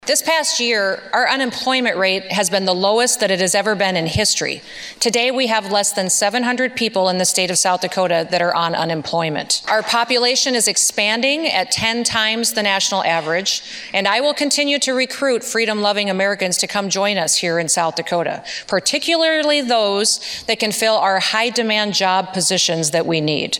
South Dakota Governor Kristi Noem delivered her 2022 Budget Address today (Dec. 6, 2022), outlining her spending plan for part of Fiscal Year 2023 and all of Fiscal Year 2024.